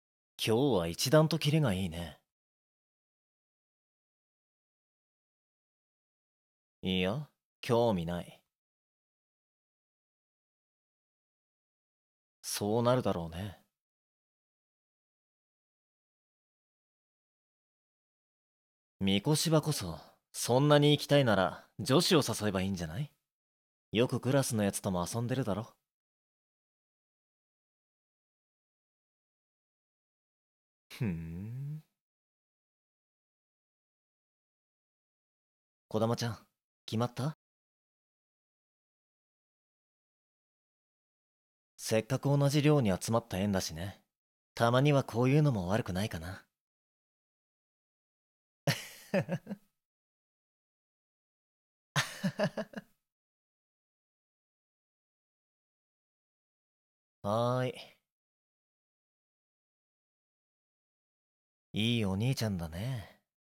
ルリアン声劇⚔終盤